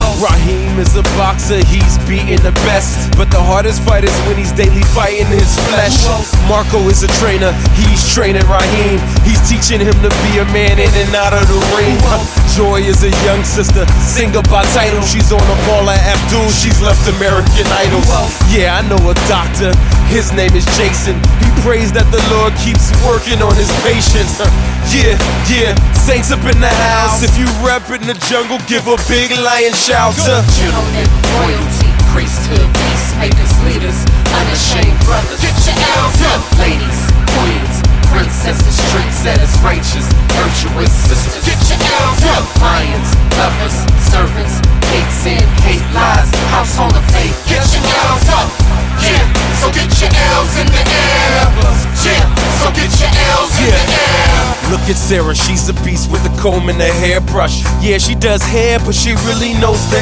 Rock-, Pop-, Motown-, Soul- und Black Gospel-Einflüsse
• Sachgebiet: Pop